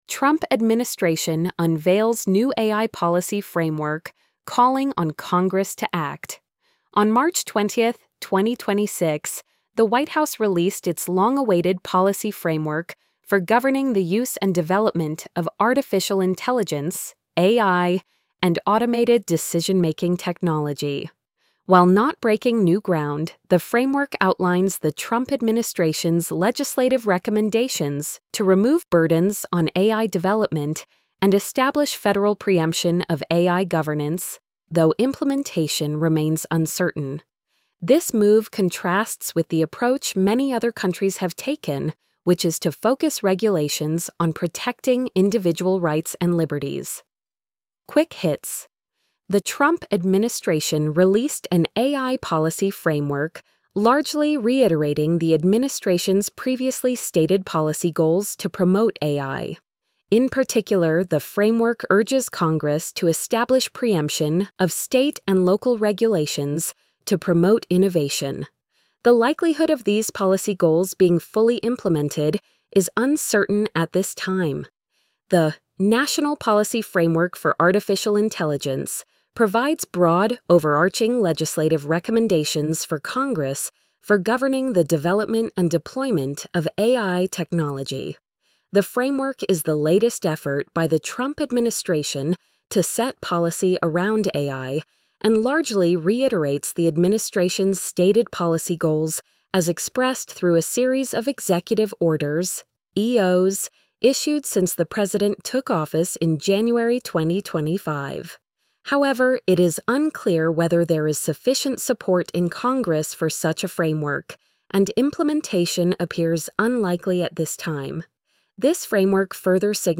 trump-administration-unveils-new-ai-policy-framework-calling-on-congress-to-act-tts-1.mp3